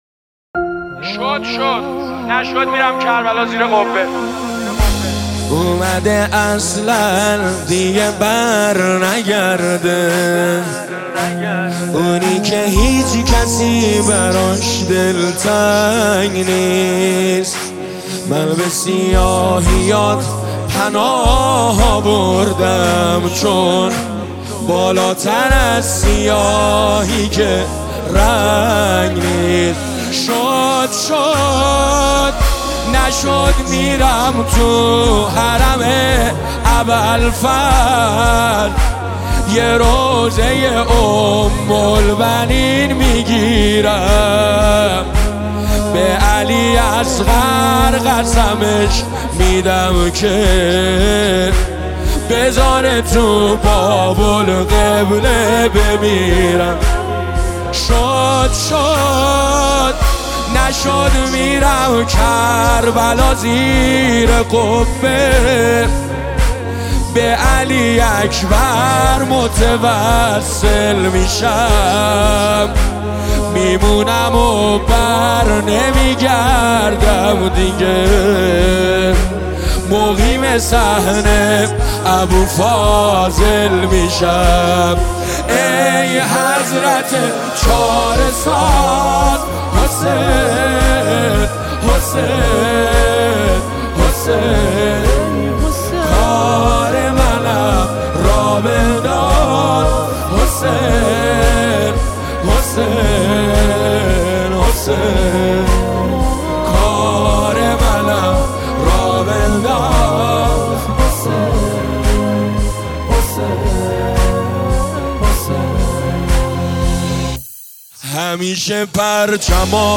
مداحی شد شد نشد میرمی کربلا